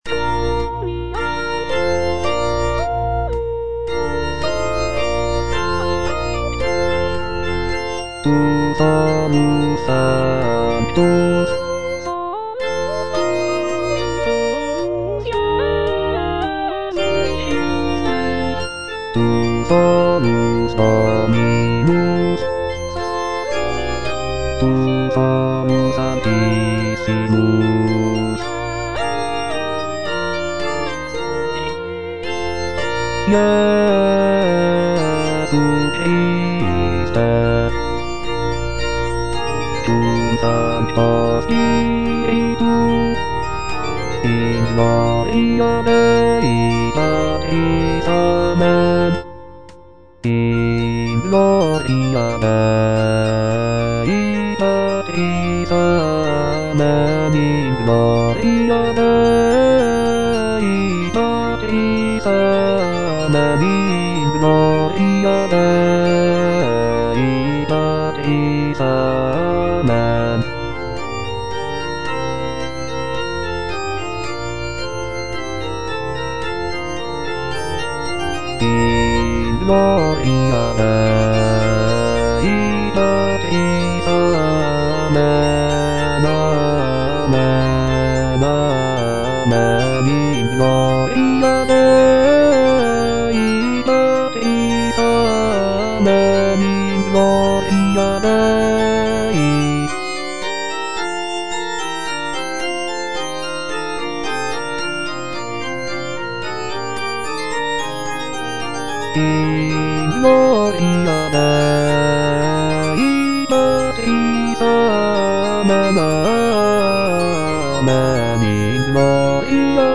A. BRUCKNER - MISSA SOLEMNIS WAB29 4. Quoniam (bass II) (Voice with metronome) Ads stop: